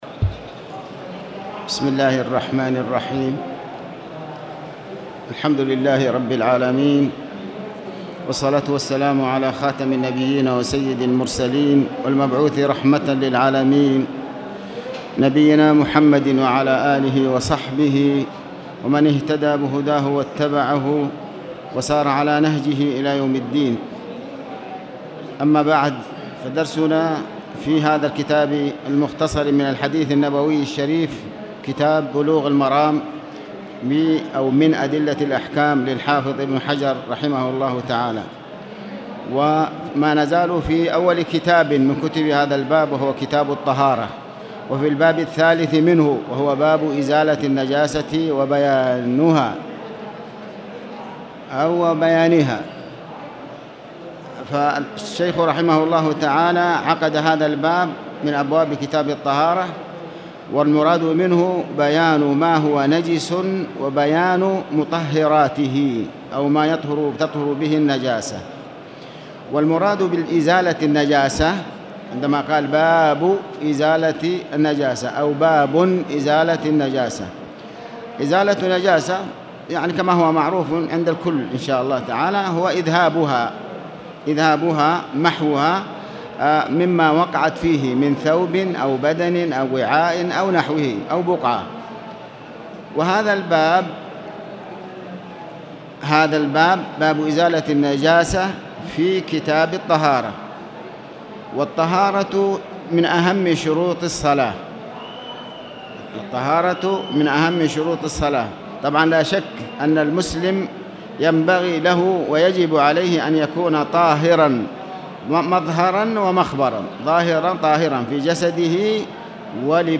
تاريخ النشر ١٠ صفر ١٤٣٨ هـ المكان: المسجد الحرام الشيخ